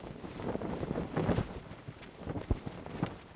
cloth_sail11.L.wav